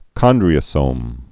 (kŏndrē-ə-sōm)